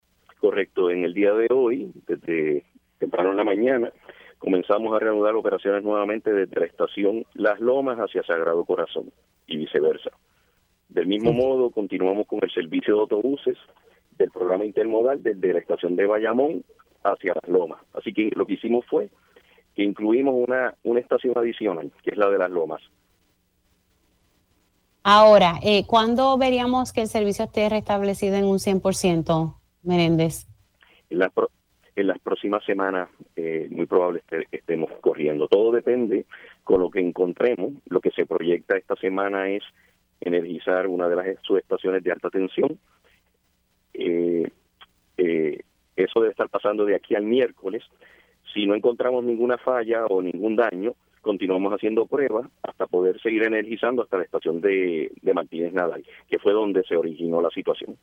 El director de Autoridad de Transporte Integrado (ATI), Josué Menéndez informó en Pega’os en la Mañana que los gastos en trabajos de arreglo de la subestación del Tren Urbano, que sufrió un fuego el pasado 14 de mayo, han superado los cinco millones de dólares.